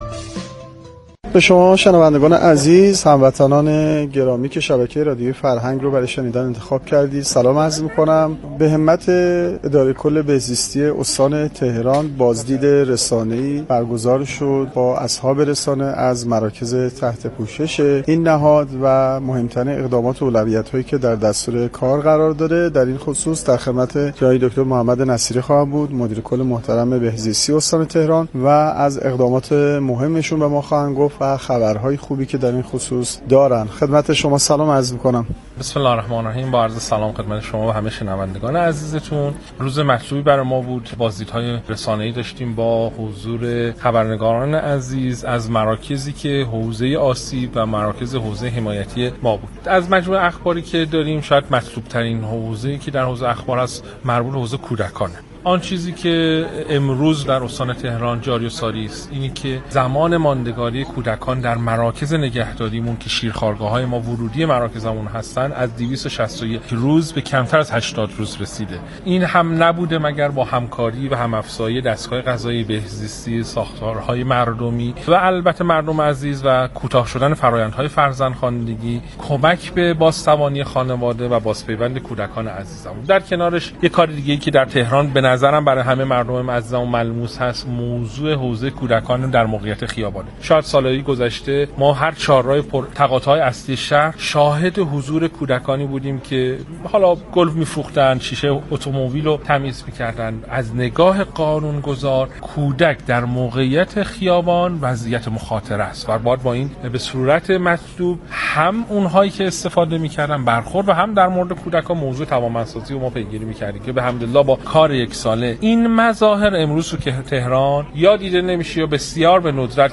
بشنویم| گفتگوی رادیویی مدیر کل بهزیستی استان تهران با رادیو فرهنگ
محمد نصیری مدیر کل بهزیستی استان تهران در حاشیه نشست خبری با رادیو فرهنگ گفت و گو کرد.